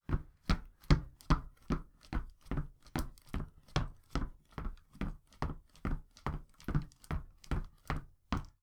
Footsteps
running-shoes-2.wav